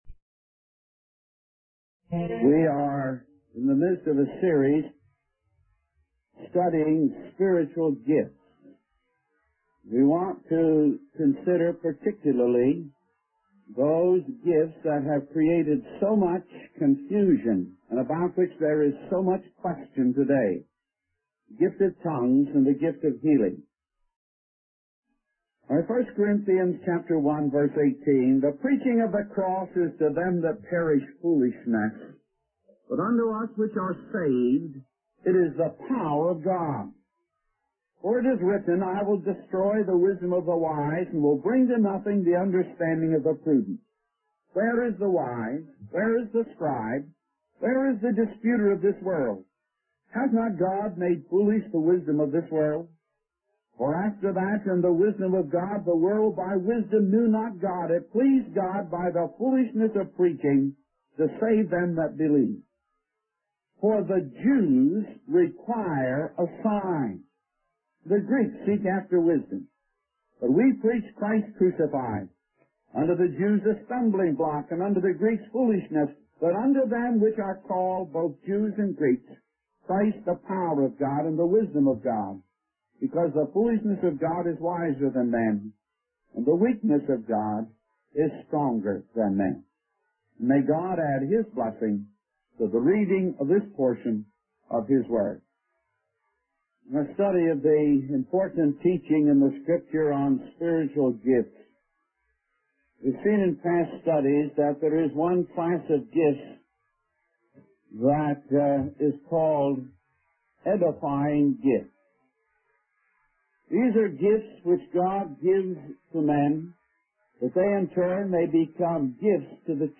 In this sermon, the preacher discusses the story of Moses and his doubts about being accepted as a deliverer by his own people.